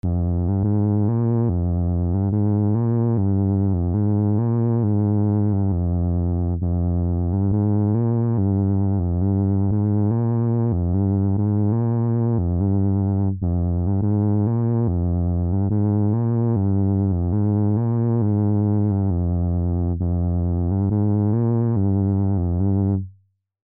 Приятные треки,с кислинкой,я такое люблю.
Скину тоже немного своего железного с одного из лайвов,по этому звук всратый.